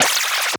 REWIND_FAST_FORWARD_09.wav